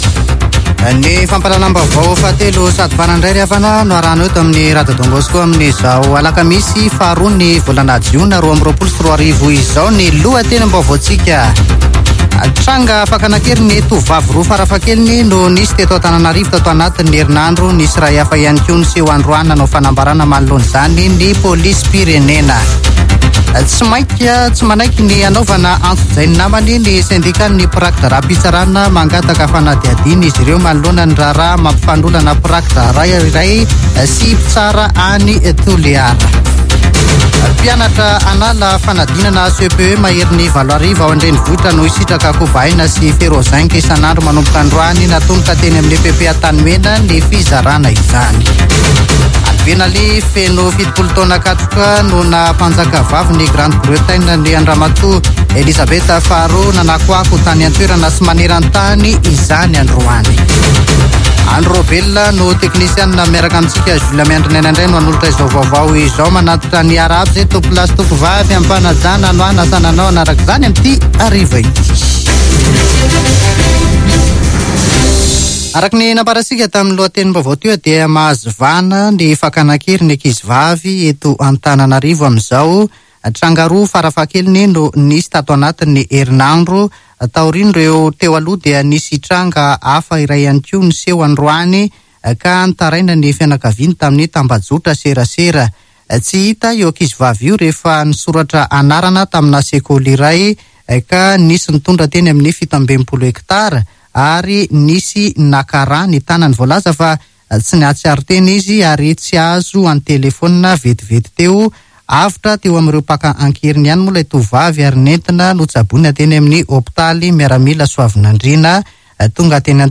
[Vaovao hariva] Alakamisy 2 jona 2022